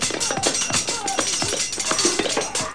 swords5.mp3